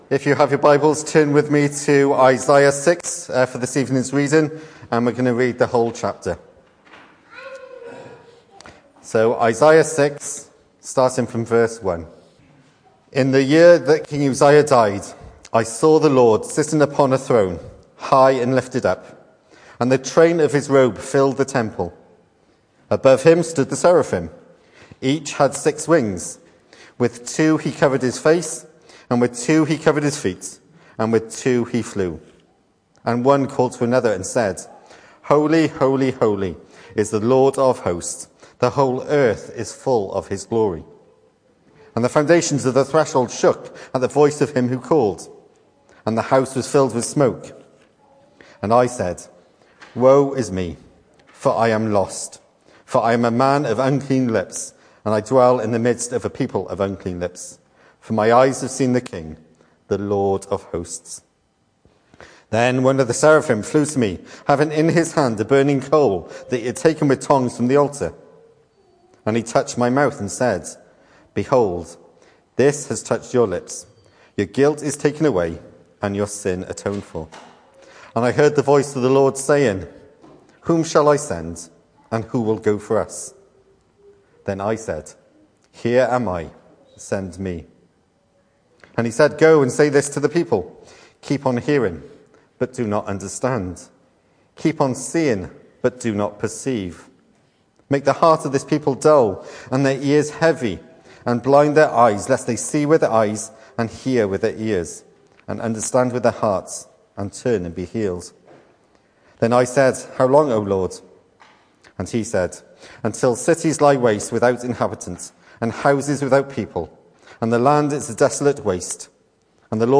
The 9th of February saw us host our Sunday morning service from the church building, with a livestream available via Facebook.